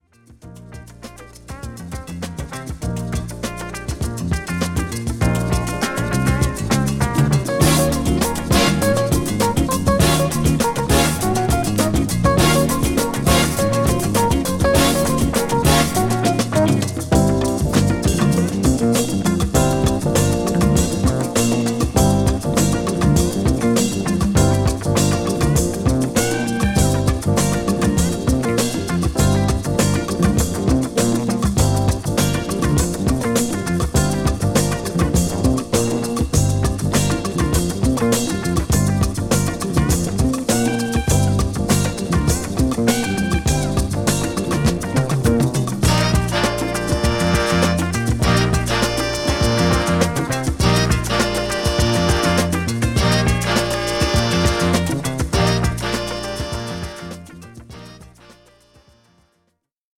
グルーヴィなインスト・チューン